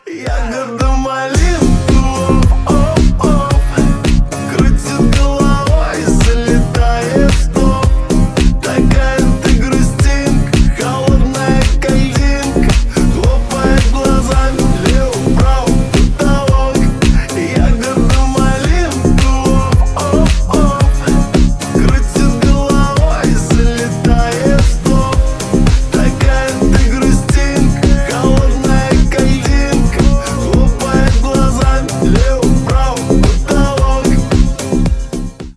• Качество: 320 kbps, Stereo
Ремикс